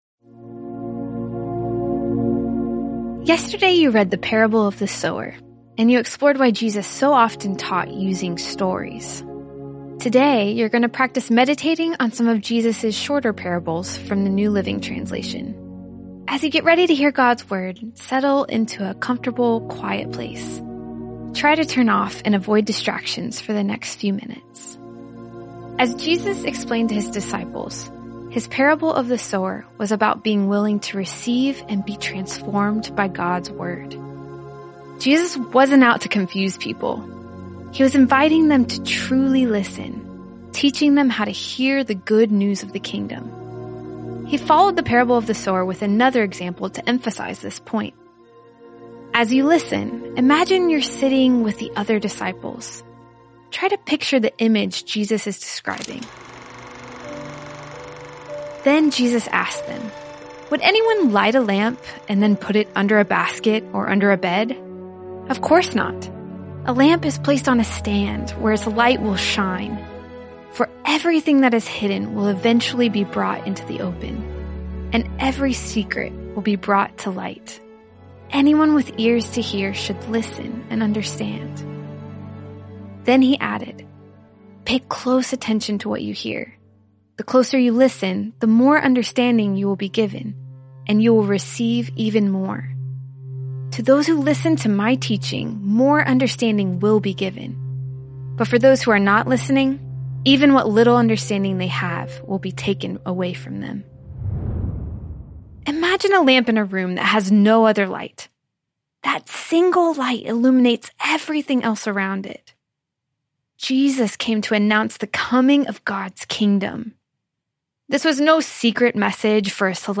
Today you’ll be meditating on a few of Jesus’ short parables. This guided reflection will last about ten minutes and uses the New Living Translation.